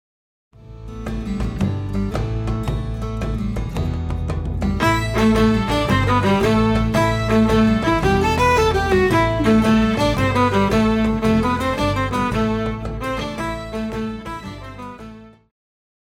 Pop
Viola
Band
Instrumental
World Music,Electronic Music
Only backing